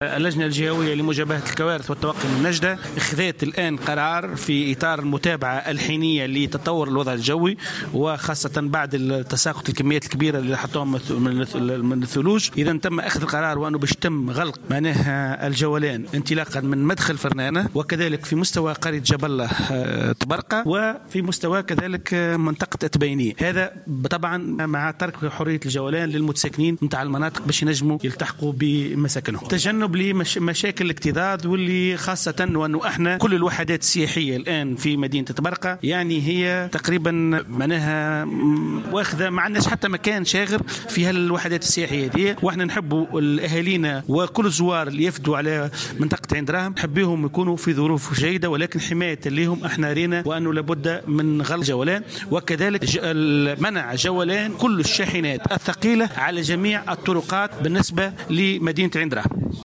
وأوضح في تصريح اليوم لمراسل "الجوهرة أف أم" أنه تم منع جولان الشاحنات الثقيلة في عين دراهم تجنبا لمشاكل الاكتظاظ، وذلك مع تواصل تساقط كميات هامة من الثلوج على المنطقة.